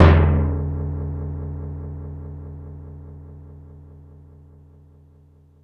BassDrum Orch X5.wav